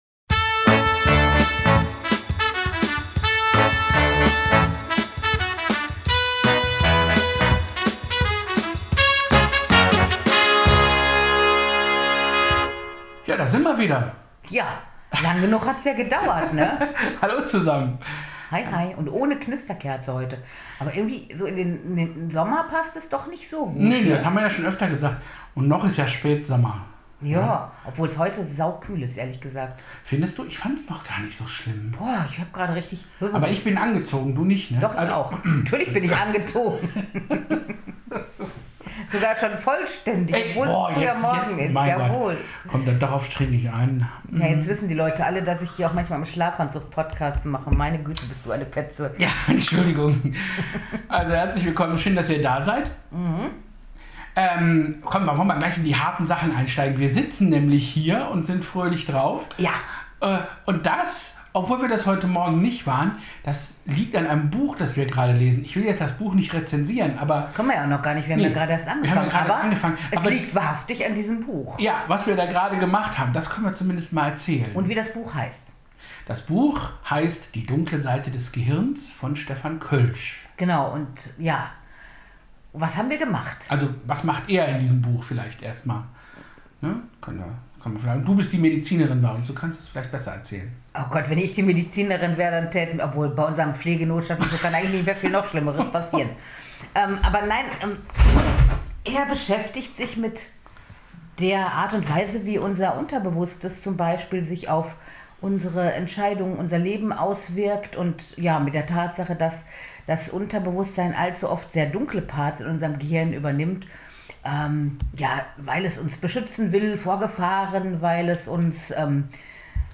Audiowa(h)ren 94: Demonstration gegen rechts und für Demokratie in Marburg am 27.01.2024